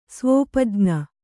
♪ svōpajña